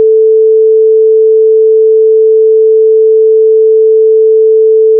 Here’s a couple of 5 second tones. 32-bit float mono and 32-bit float stereo.